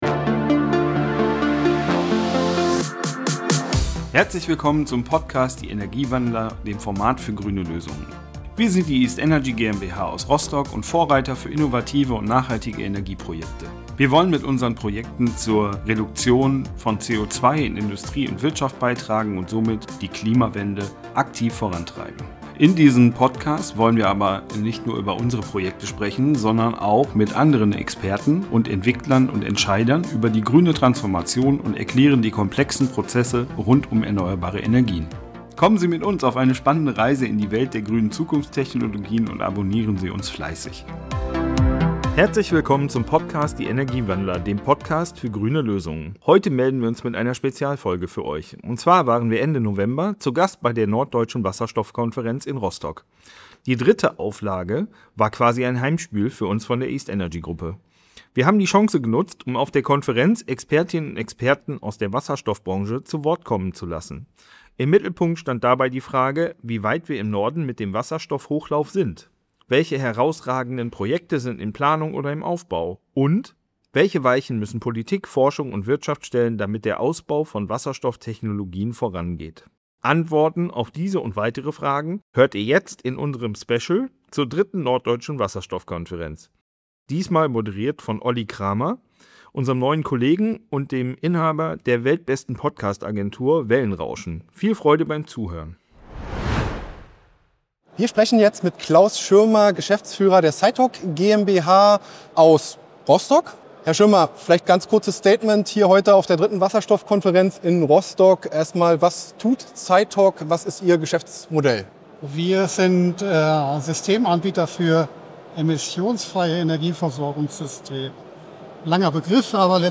Meinungen und Impulse von der 3. Norddeutschen Wasserstoffkonferenz in Rostock.